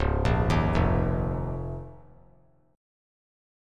C#6add9 Chord
Listen to C#6add9 strummed